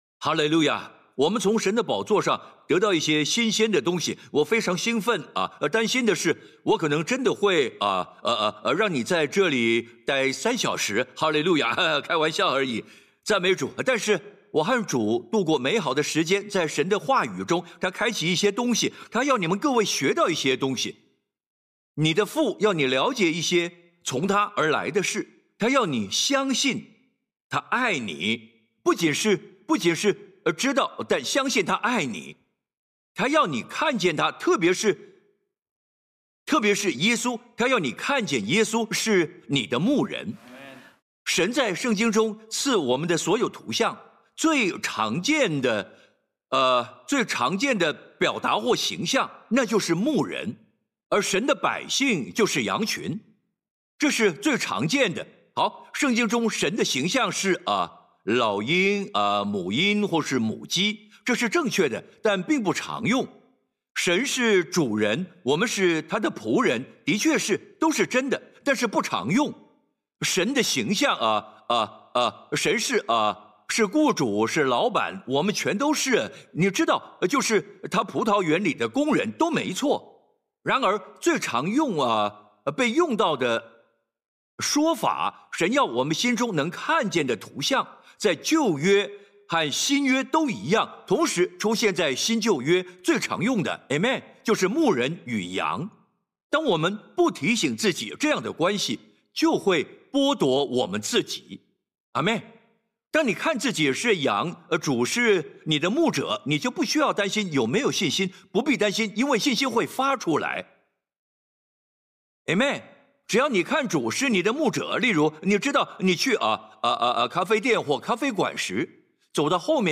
中文讲道系列